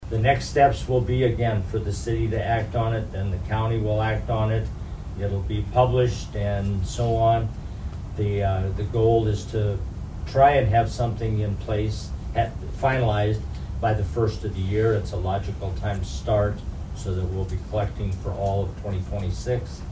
Sutton goes over the next step in the process and hope to have this done by the end of the year.